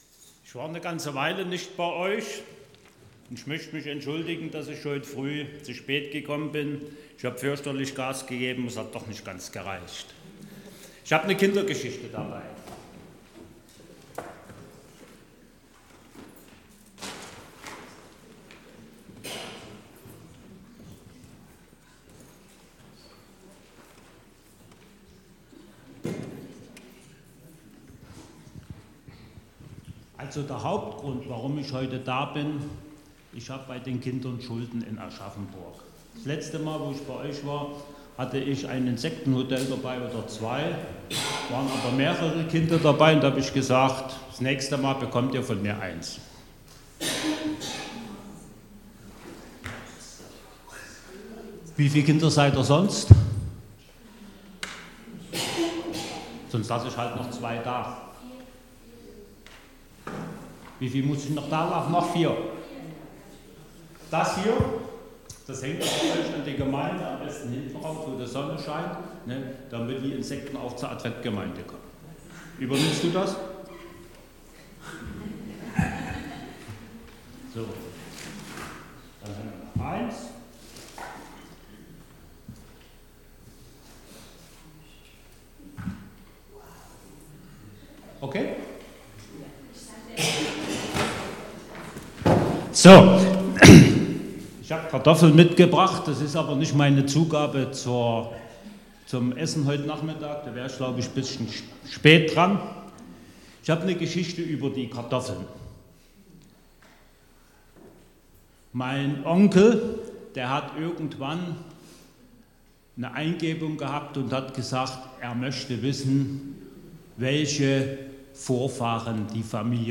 Predigten zum Anhören